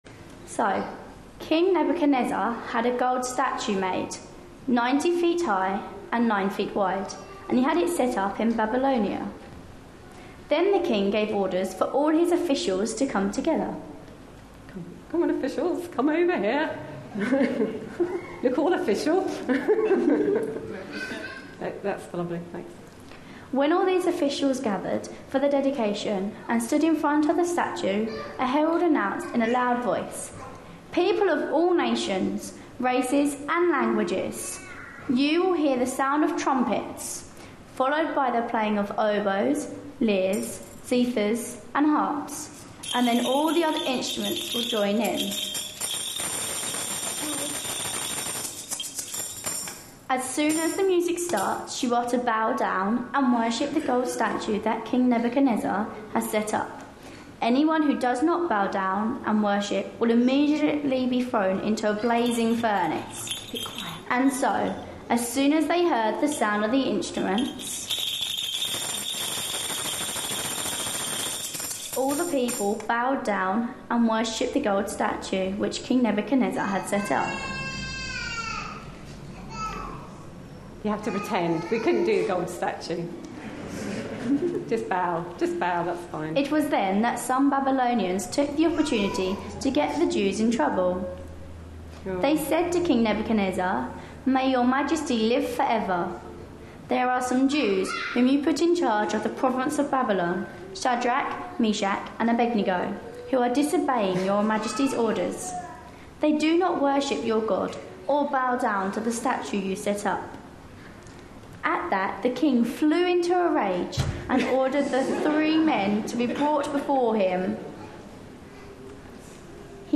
A sermon preached on 4th August, 2013.